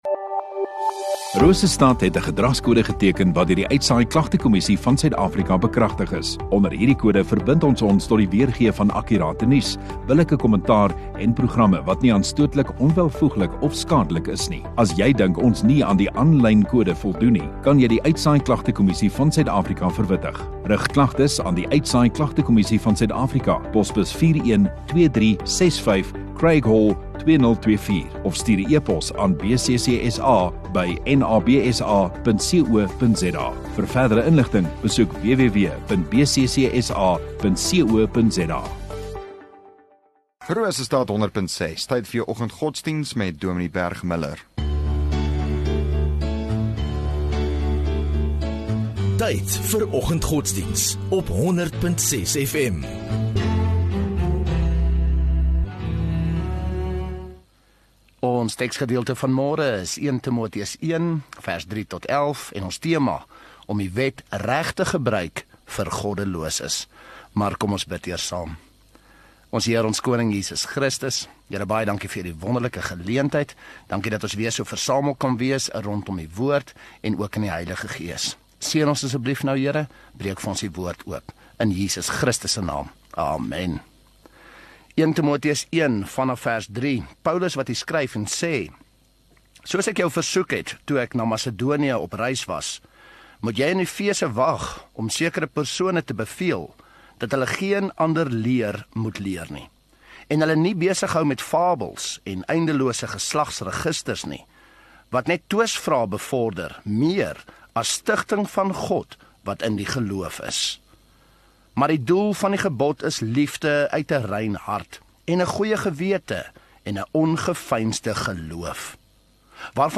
19 Mar Woensdag Oggenddiens